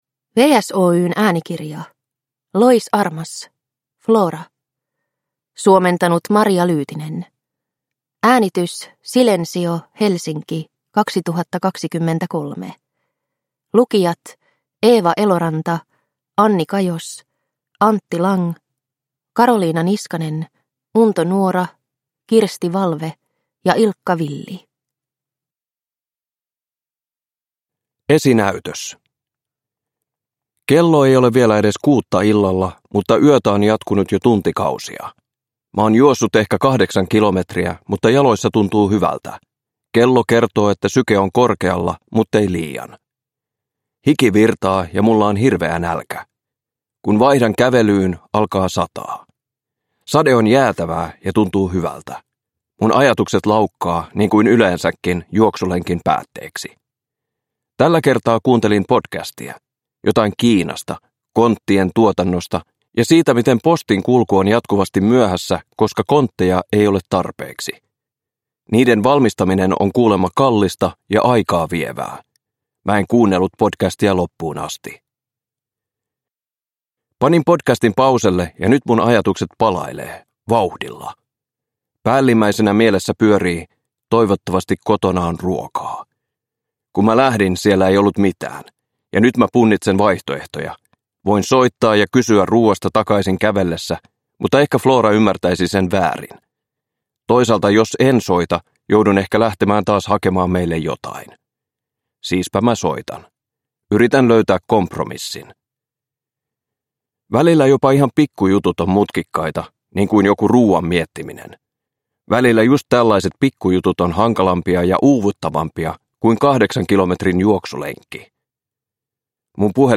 Flora – Ljudbok – Laddas ner